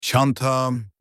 bag-in-turkish.mp3